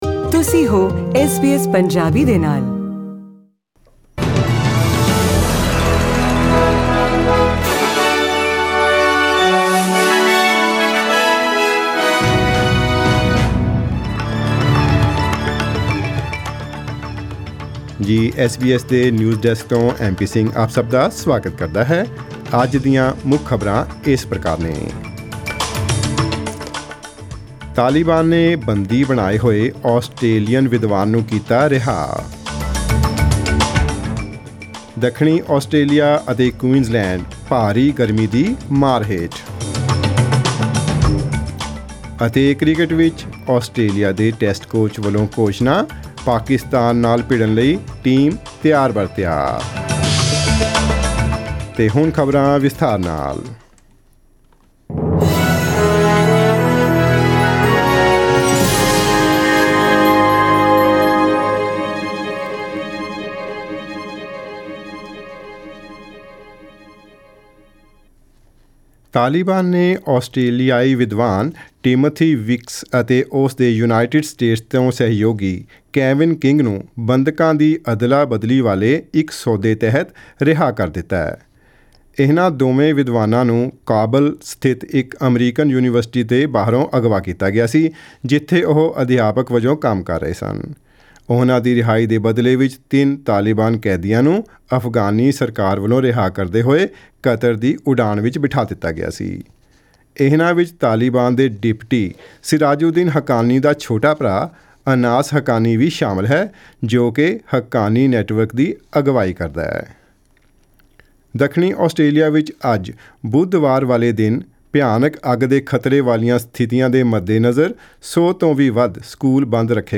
Welcome to the news bulletin Source: SBS Punjabi